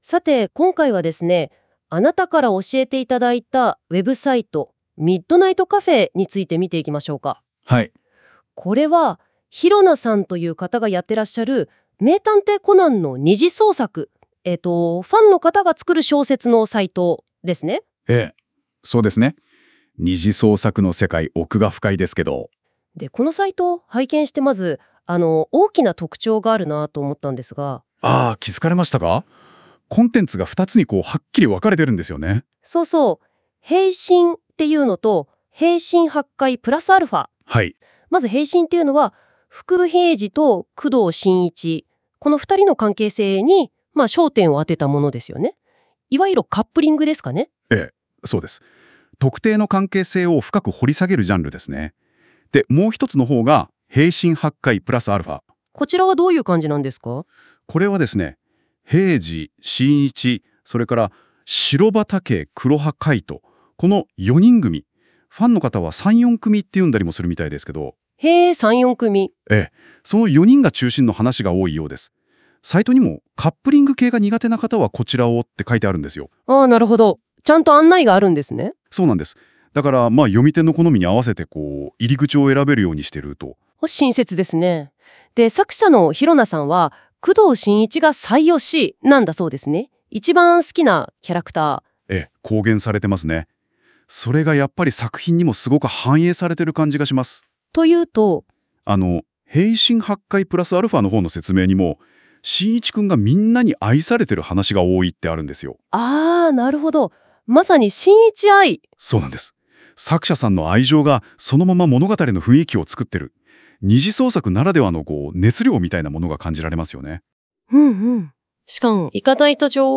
この「MidNightCafe」をAIに分析し会話してもらいました！
まるでポッドキャストかラジオ番組のように、男女の会話形式でやりとりしています。
しかも男性の方が、このサイトを女性に紹介しているというカオスな状態（笑）
元は英語圏なせいか、日本語の「読み」には弱いらしく、いくら読み仮名を設定したり、ふりがな振ったりしても、どうしても固有名詞が直らない等の不具合はありますが、それを凌駕するクオリティの高さです。
10回以上、サイトのTOPページの説明文章を変えたり、ソースコード上でフリガナを振ってみたりしてたんですが･･･服部平次を「ふくぶへいじ」と言ってしまったり･･･